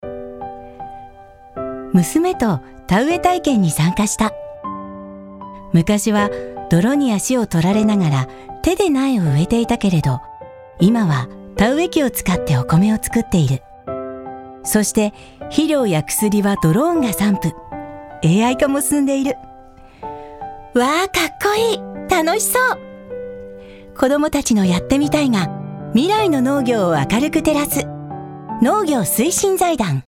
声を聴く Voice Sample
4.モノローグ